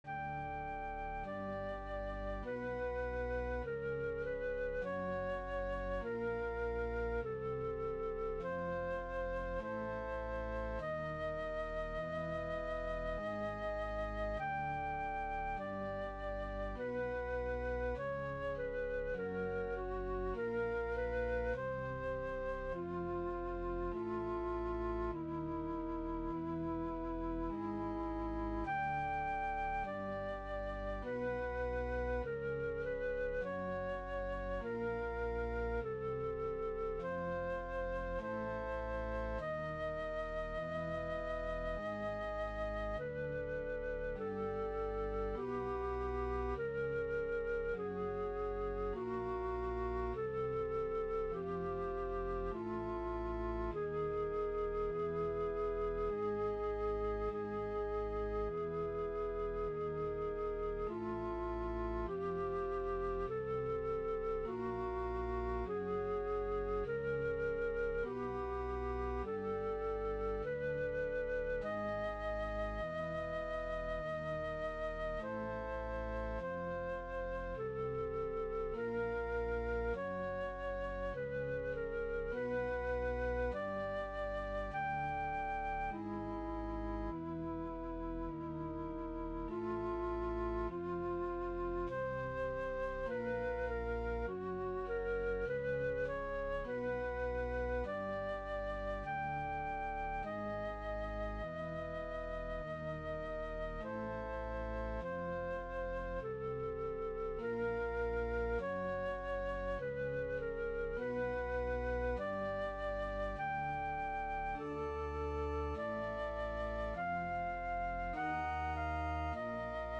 Piece for flute and organ.
I came up with a 12-tone row based on what I thought Dylan might have meant by this passage (like many other things Dylan has said, what exactly he meant has been a subject of heated debate in online Dylan forums), and used this in writing the bass part.